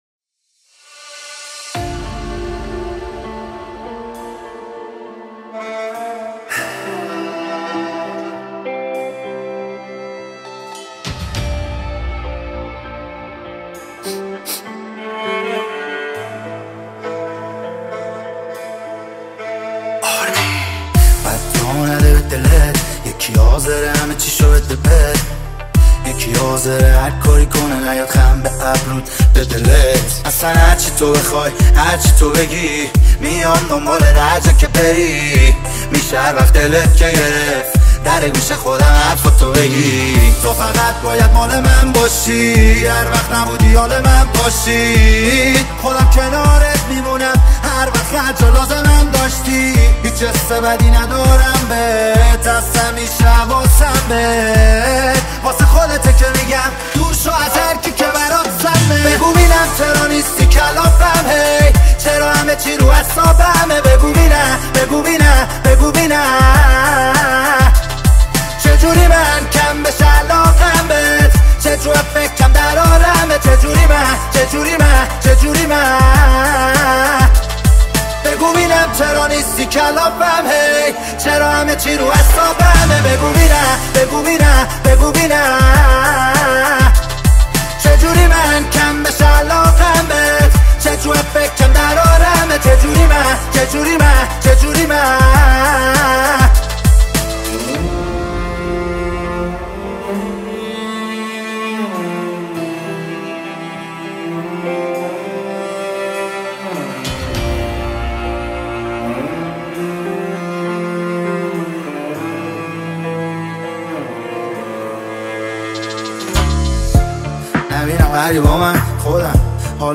با فضای احساسی
عاشقانه فارسی